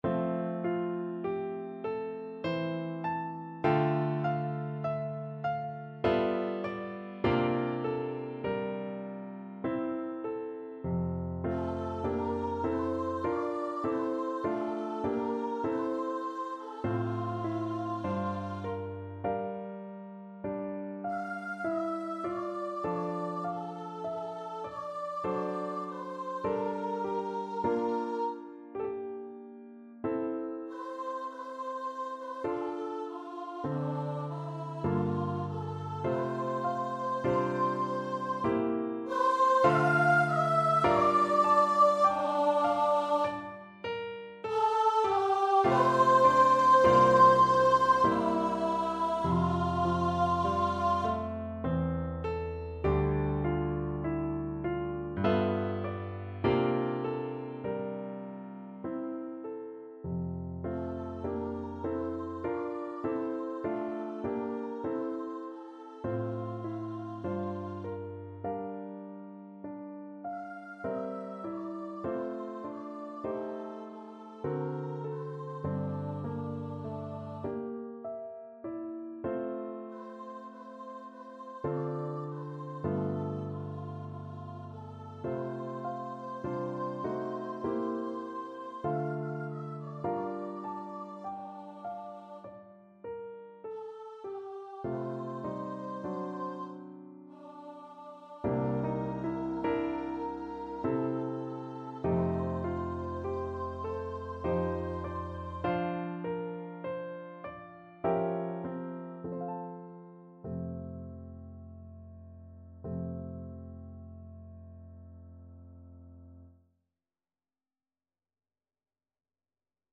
~ = 50 Slow, with emphasis
3/4 (View more 3/4 Music)
Classical (View more Classical Voice Music)